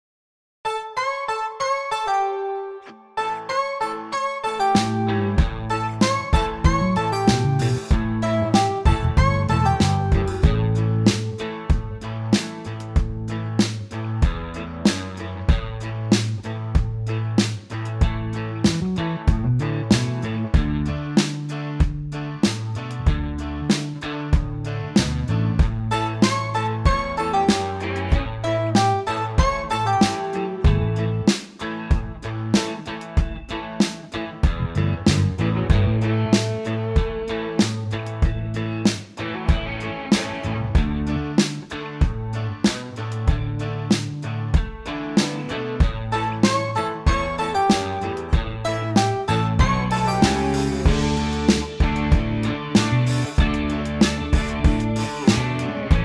Unique Backing Tracks
GUITAR SOLO REMOVED!
4 bar intro and vocal in at 10 seconds
key - A - vocal range - A to A
live version, same key.